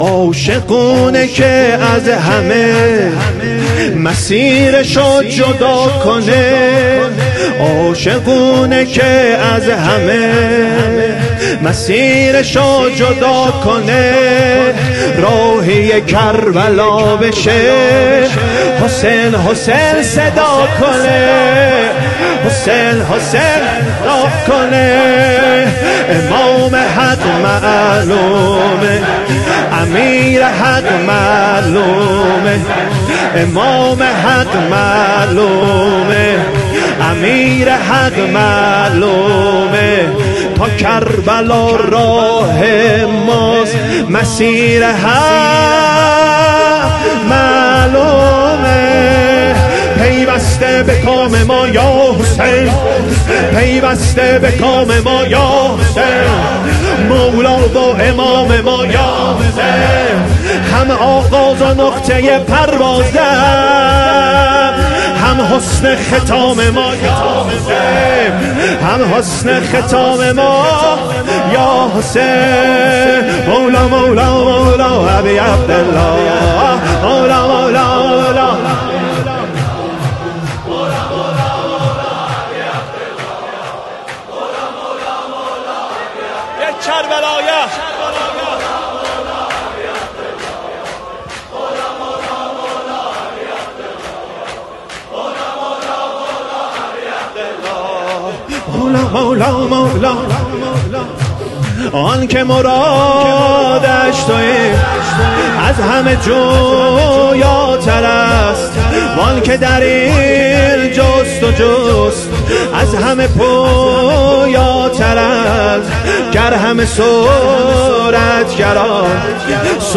شور
سینه زنی شور عاشق اونه که از همه مسیرشو جدا کنه
ایام فاطمیه دوم - شب سوم